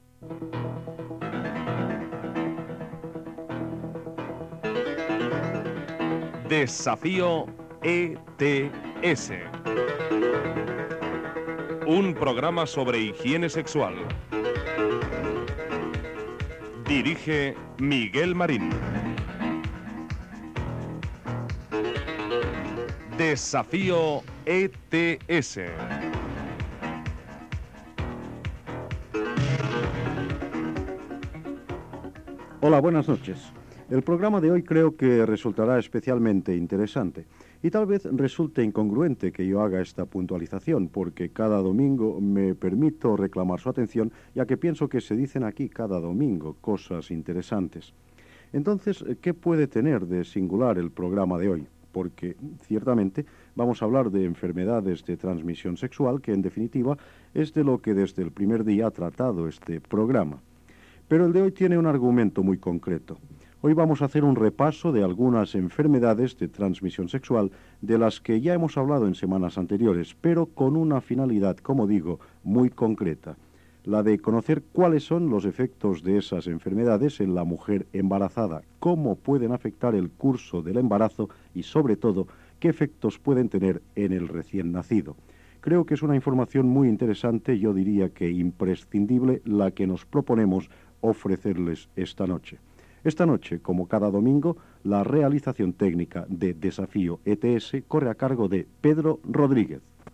Careta i sumari del programa sobre higiene sexual.
Divulgació